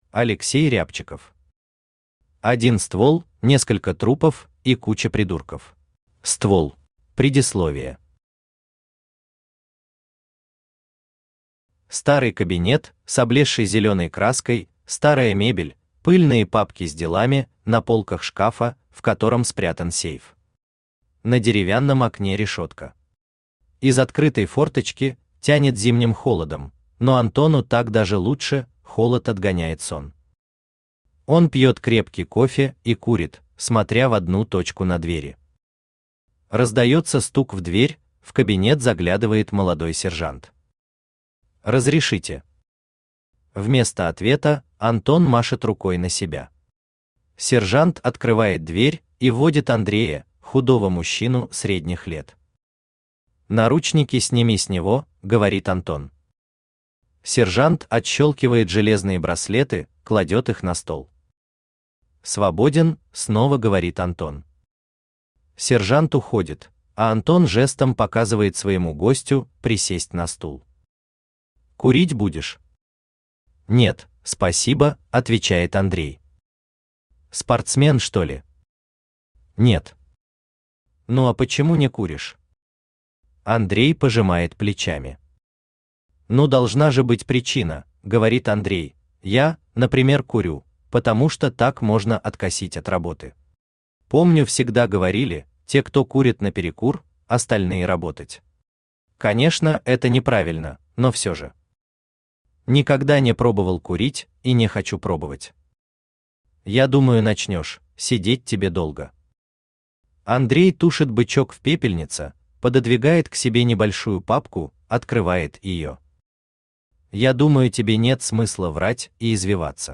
Аудиокнига Один ствол, несколько трупов и куча придурков | Библиотека аудиокниг
Aудиокнига Один ствол, несколько трупов и куча придурков Автор Алексей Рябчиков Читает аудиокнигу Авточтец ЛитРес.